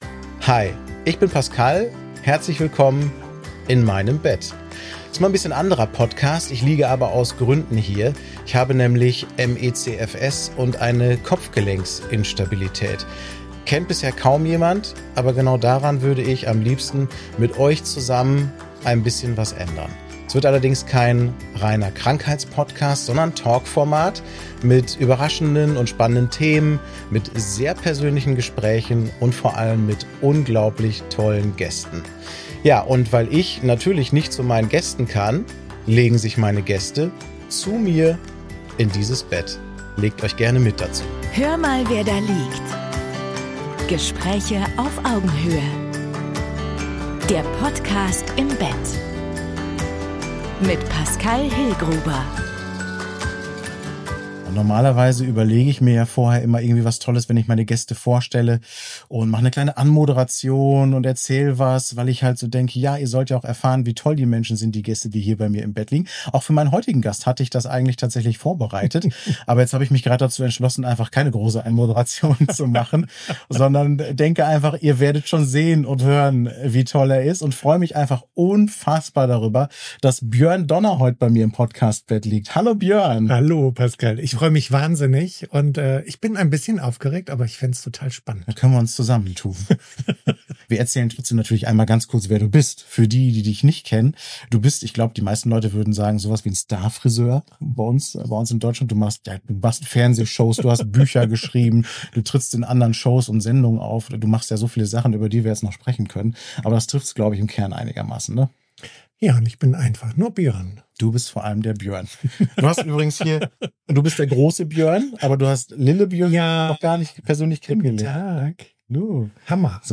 spricht mit mir im Bett